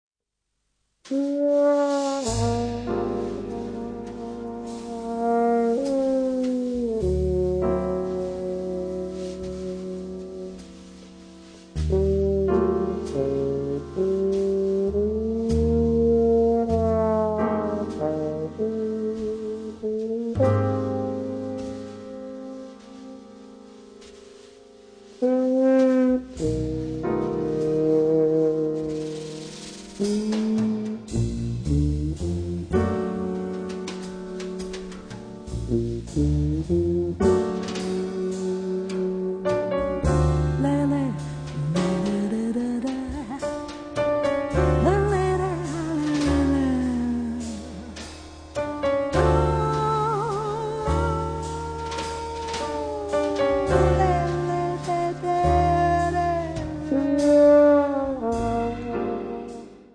piano
contrabbasso
batteria
voce
dall'inconfondibile timbro ed estensione vocale.